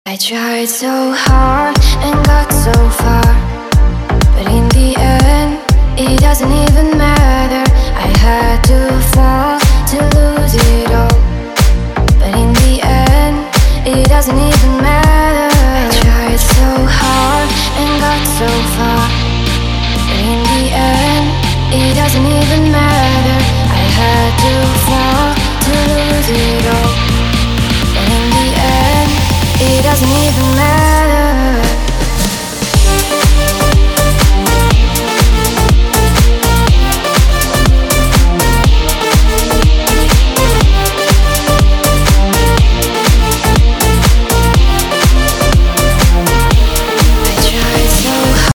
• Качество: 320, Stereo
deep house
ремиксы
Стиль: Deep House